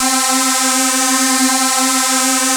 HI PAD.wav